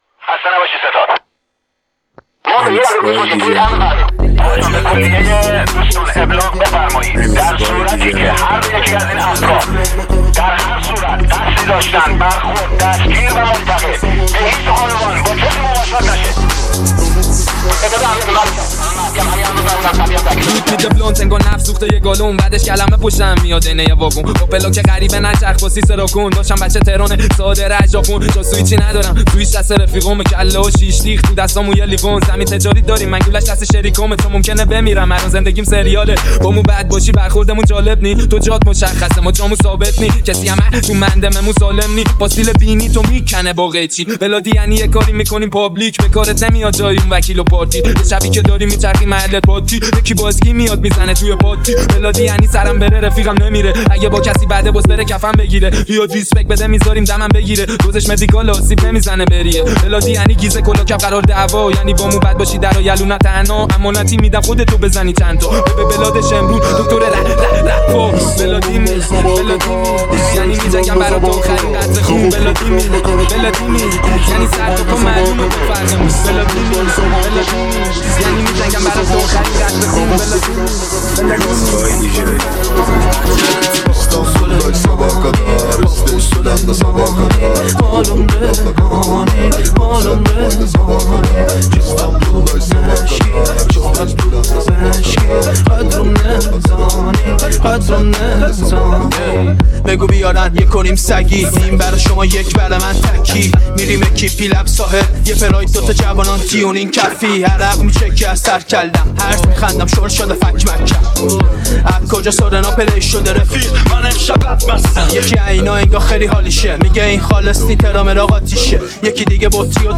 ریمیکس خفن مخصوص رقص و پارتی
ریمیکس خفن رپ
ریمیکس خفن گنگ